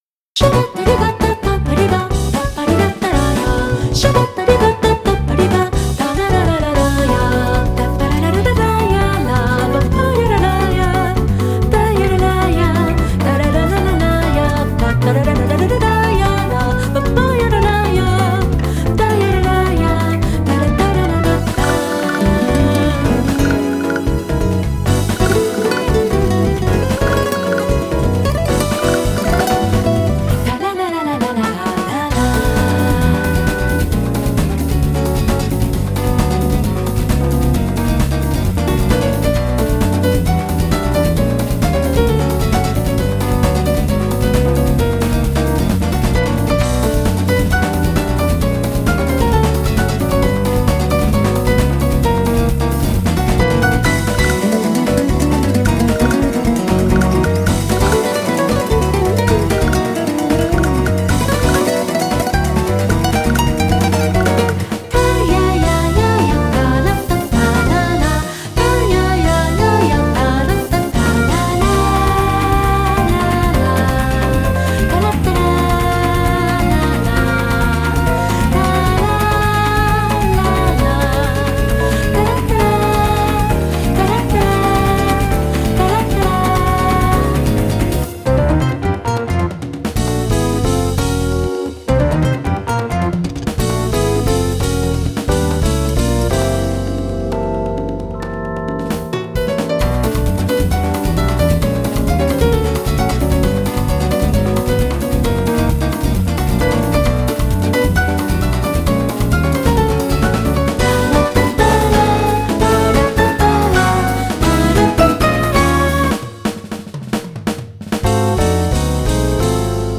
BPM132
Audio QualityPerfect (High Quality)